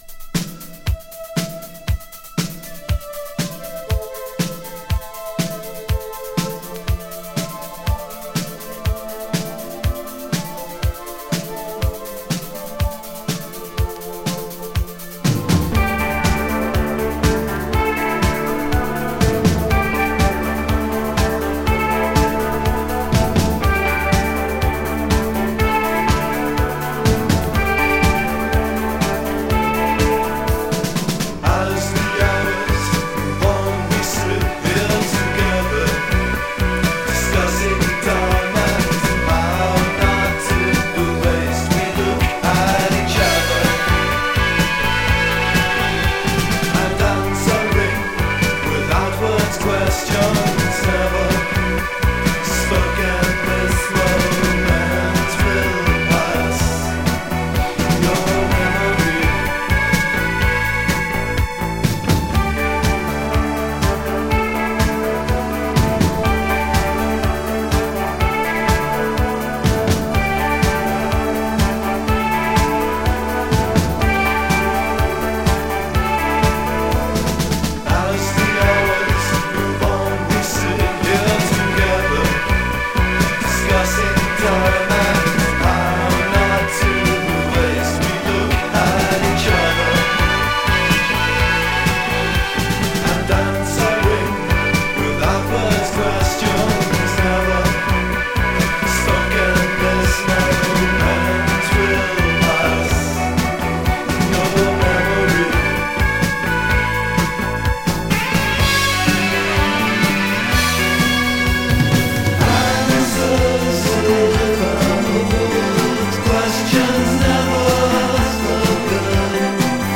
【SYNTH POP】 【NEW WAVE】